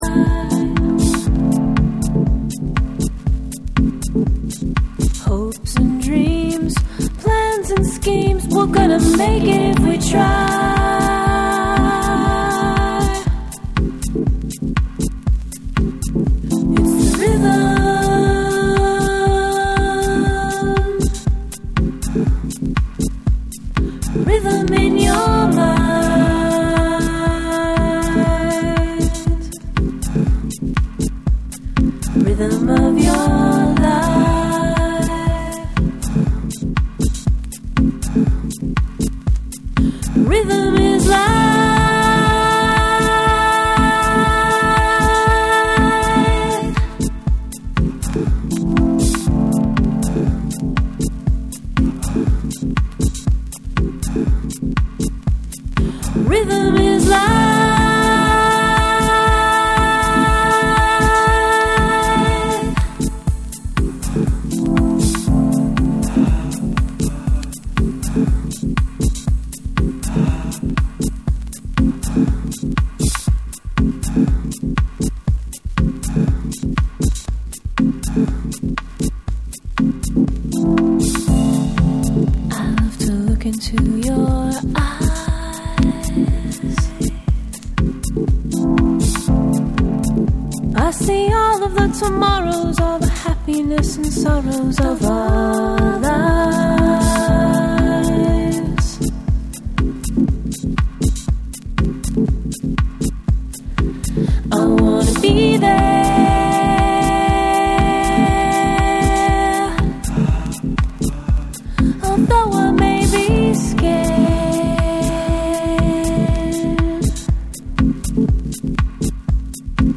、原曲のオーガニックなグルーヴがじんわり染みるALBUM VERSIONの3(SAMPLE 2)。
TECHNO & HOUSE